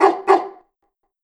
Wolf Bark Double.wav